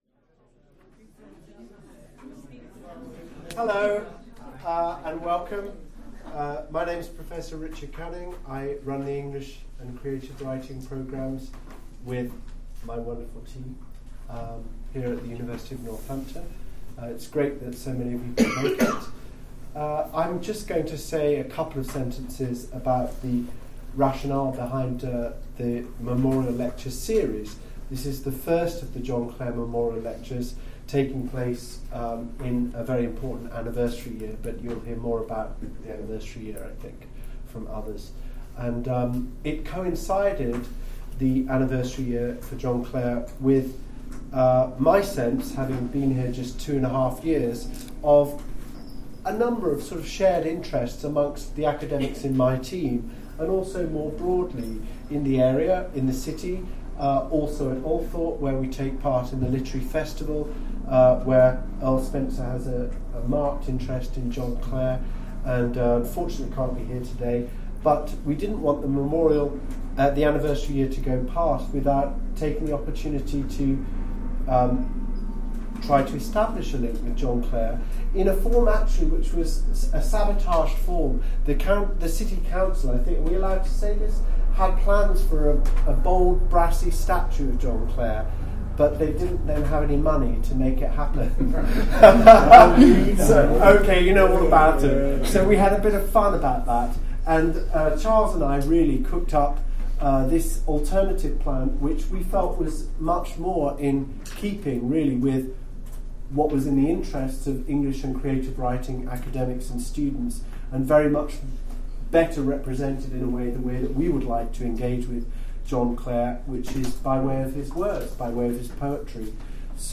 John Clare Memorial Lecture (Reception, 12 Dec 2014).